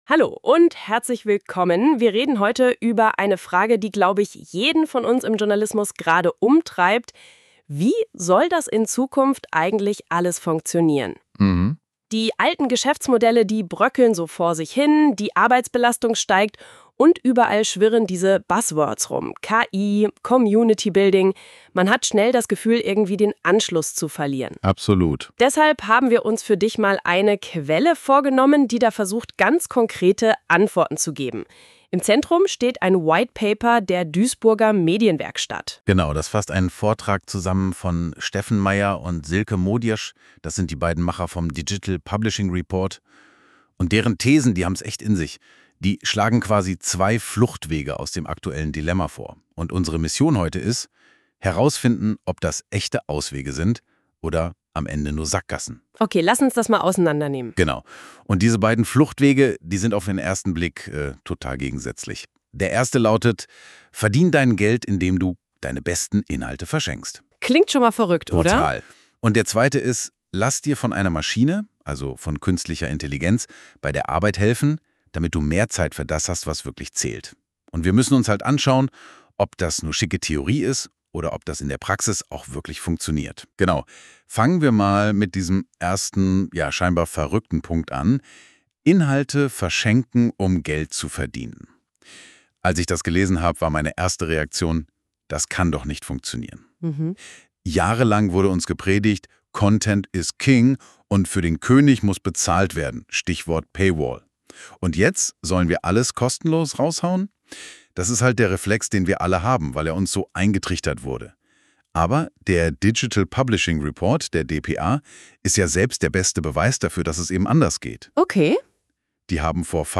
Unsere KI-Hosts diskutieren den digitalen Strukturwandel in Verlagen.